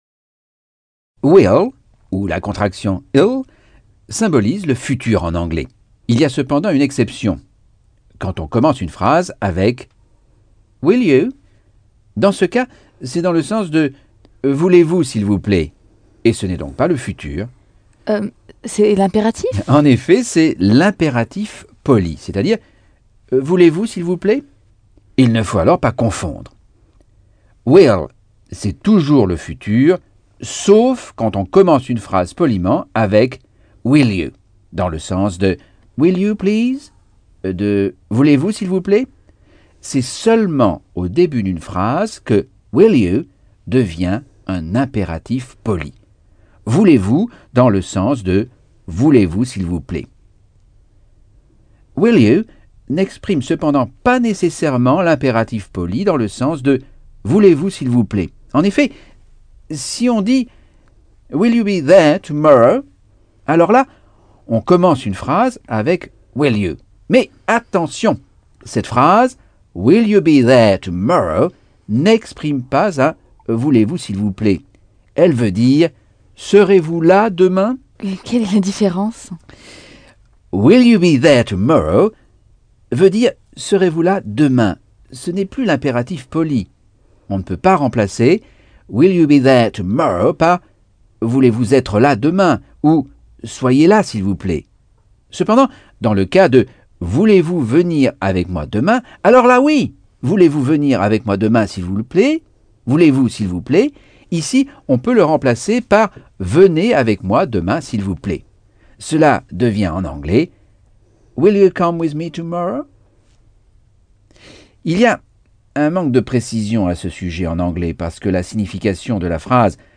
Leçon 1 - Cours audio Anglais par Michel Thomas